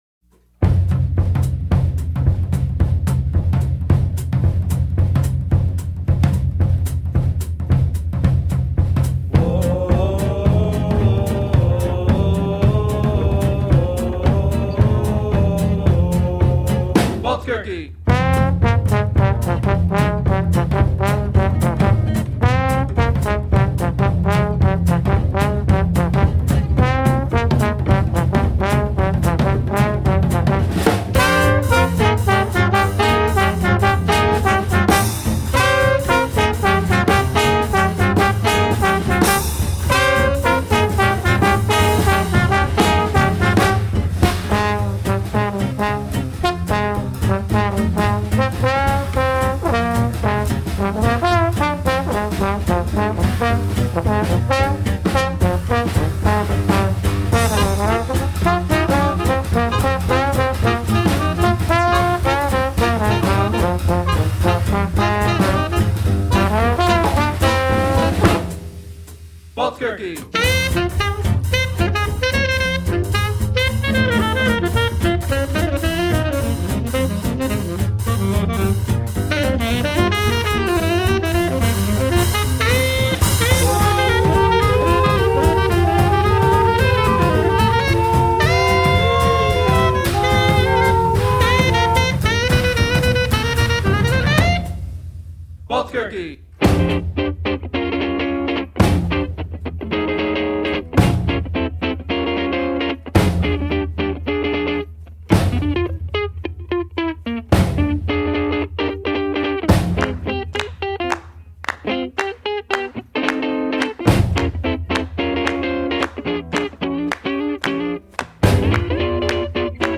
Vintage swing in the 21st century.
a fast, driving number with a nod to early jazz